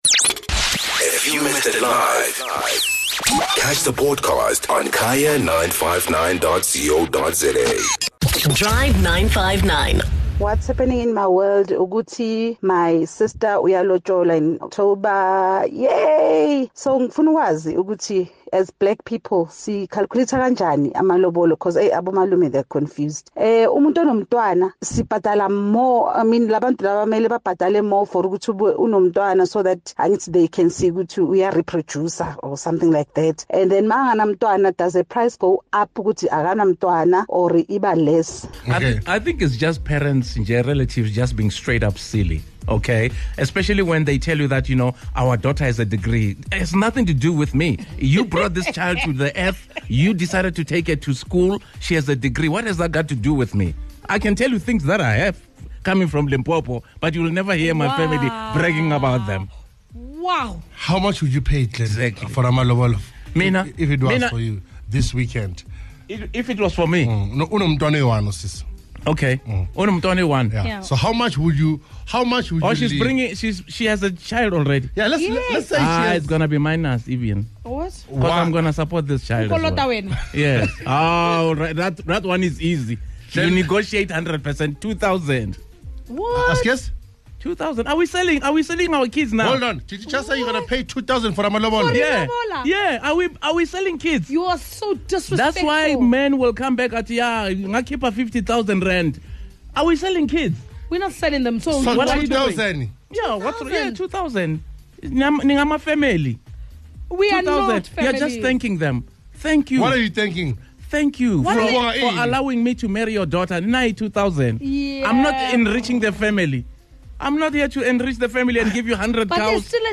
Naturally a debate ignited with men and women weighing in...